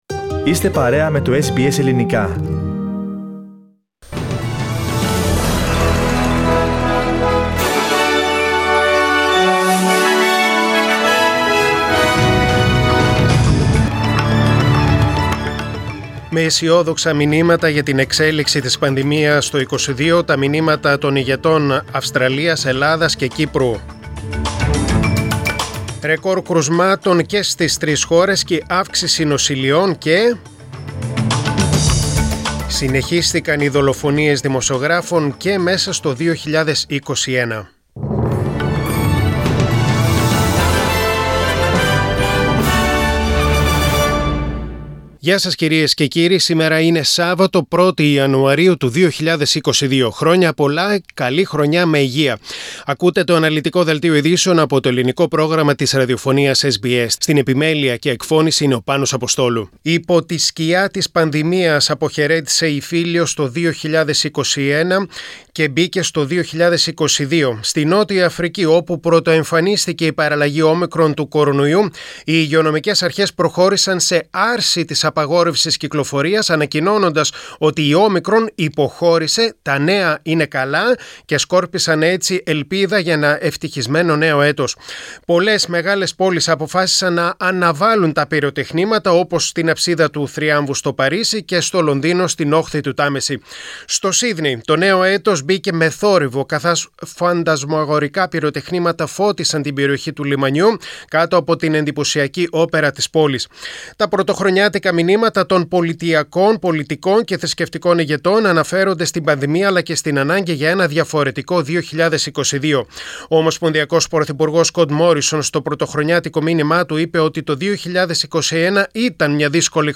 News in Greek: New Year Day, Saturday 1.1.2022